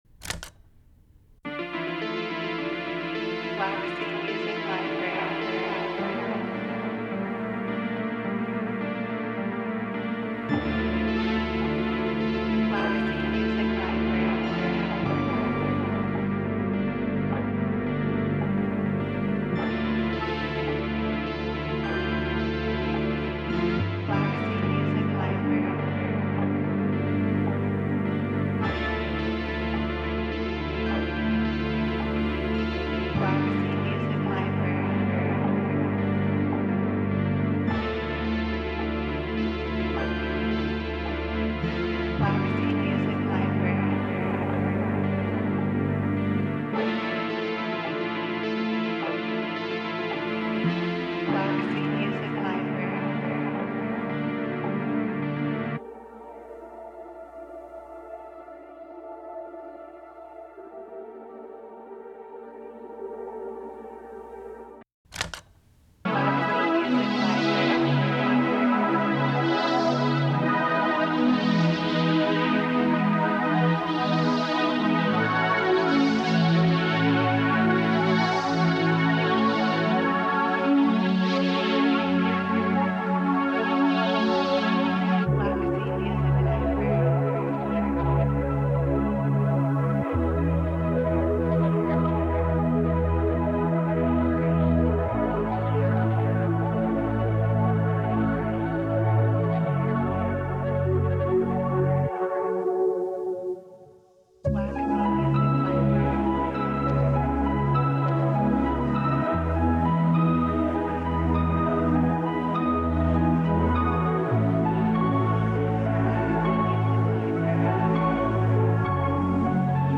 This bank includes 60 Analog Lab V Presets inspired by various films from the late 70s and 80s (think John Carpenter, Tangerine Dream, Stanley Kubrick).
This preset pack offers a variety of soundscapes from classic synth pads, powerful bass sounds, to mellow keys and dreamy plucks. All of the sounds have been designed from scratch on various synthesizers and further processed using pedals and various effects.